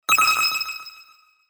爆風や爆発的な出来事が発生した瞬間のような効果音。